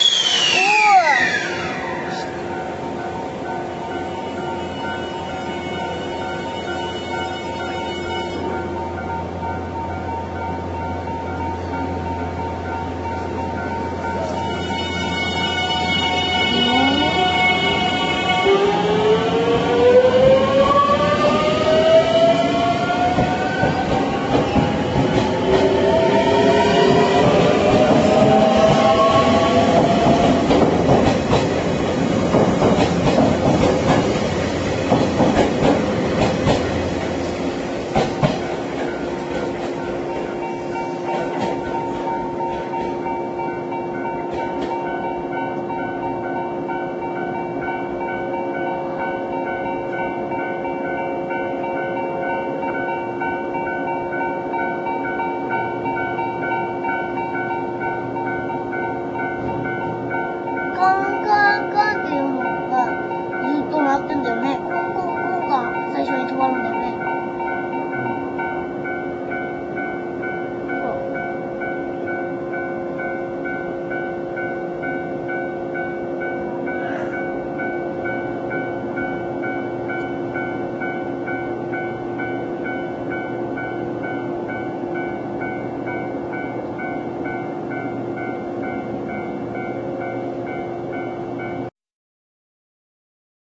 長野駅からの発車シーン(918KB)
エアーの音とVVVVFインバーターの音がたまりません。